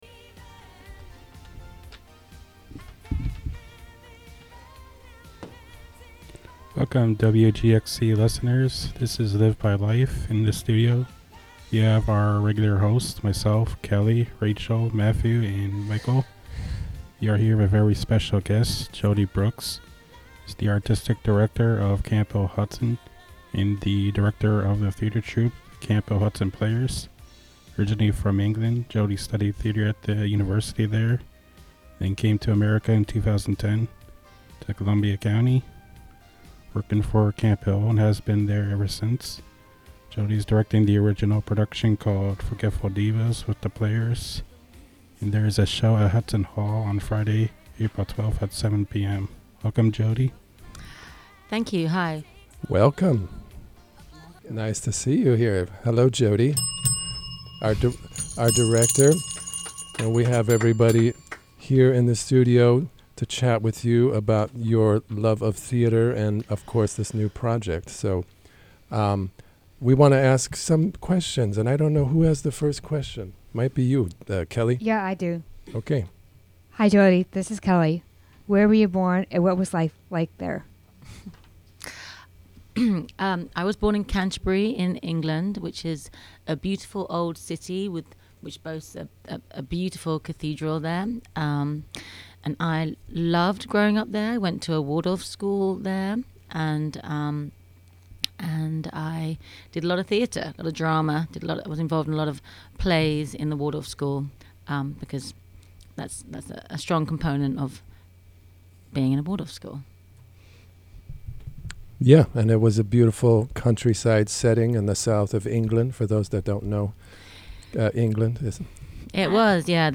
(Audio) Mar 21, 2024 shows Live By Life Produced by the Camphill Hudson Radio Group. Interview by the Camphill Media Group.